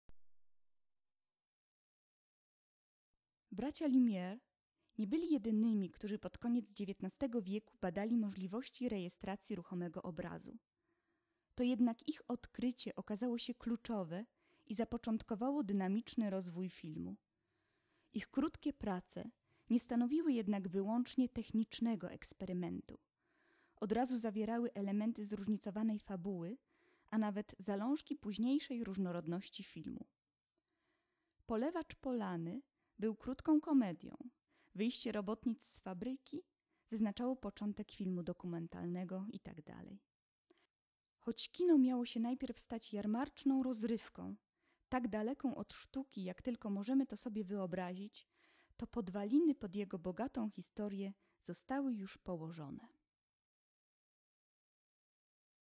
włącz  lektor